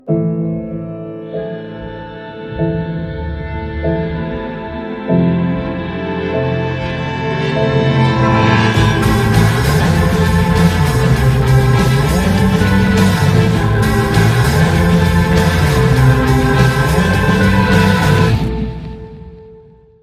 Soundtrack.